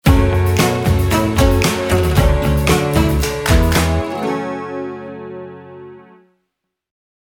Geluidslogo Sonic Brand